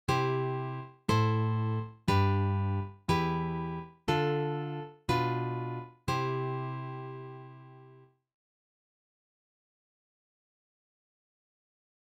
Sequences starting from  2 chords:
5. C  Am|  G  Em|  Dm  Bdim|  C [